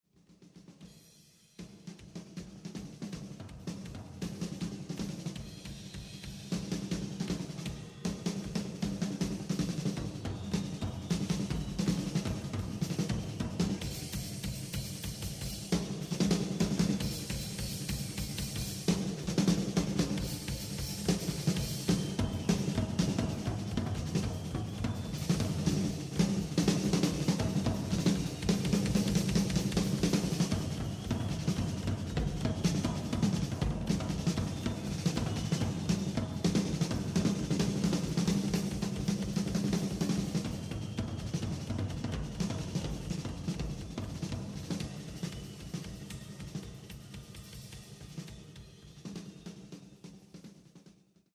batería1.mp3